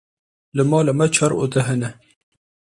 Pronunciado como (IPA)
/t͡ʃɑːɾ/